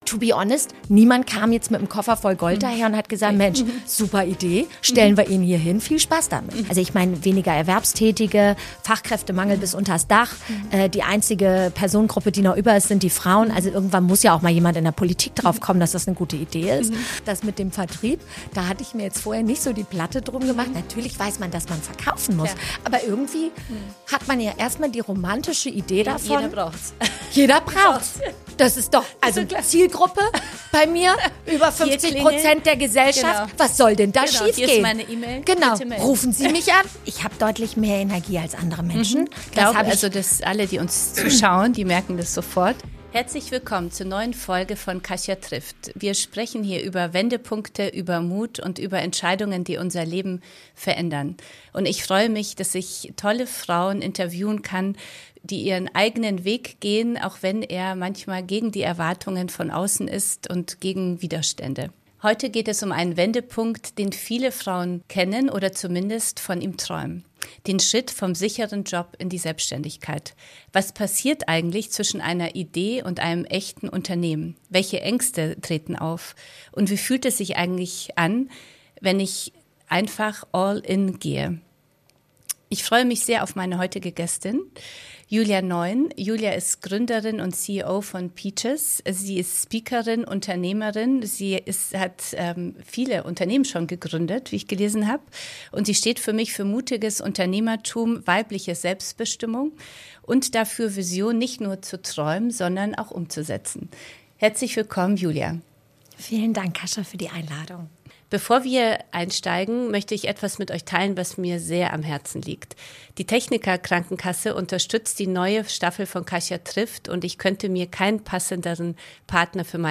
Ein Gespräch über Mut, Verantwortung und die Realität hinter dem romantisierten Bild vom Gründen. Über Selbstzweifel, finanzielle Unsicherheit und sieben Tage Arbeit pro Woche.
Ein ehrliches Gespräch über Durchhaltevermögen, Klarheit und die Entscheidung, das eigene Potenzial nicht länger aufzuschieben.